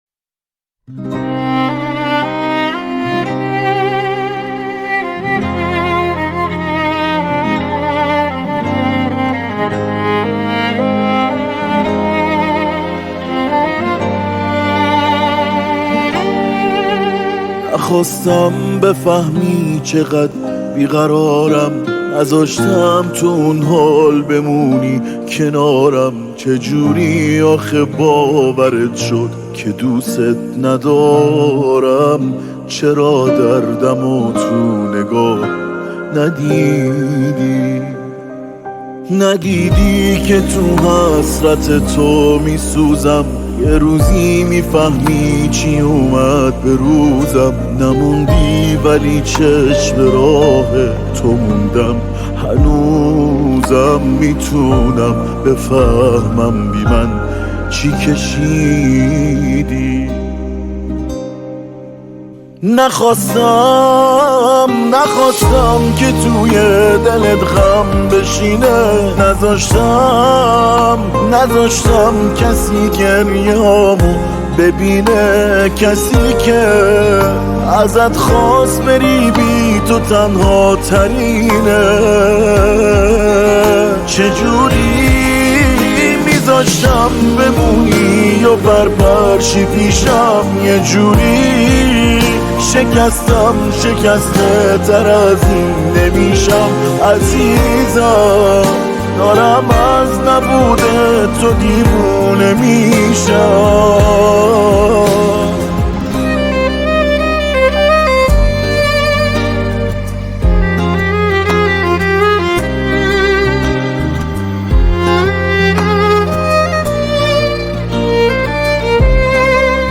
آهنگ فارسی